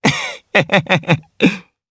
Kibera-Vox_Happy2_jp.wav